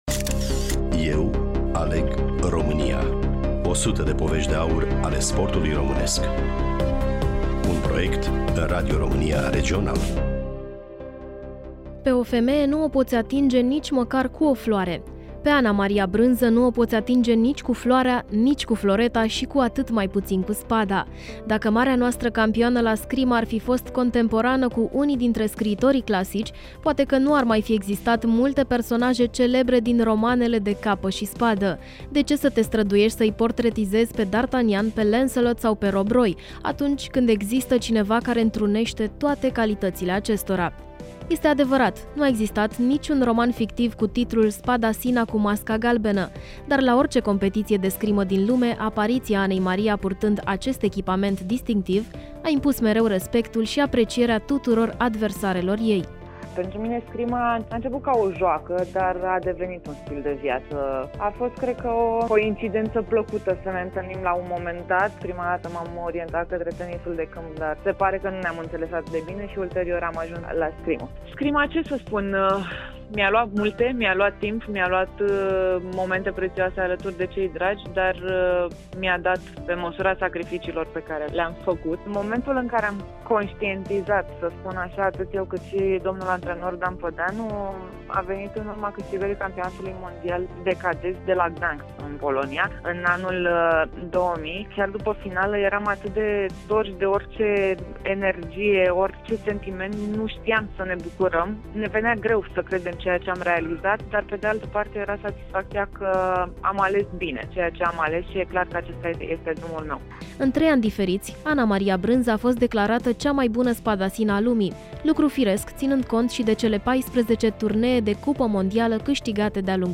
Studioul: Bucuresti FM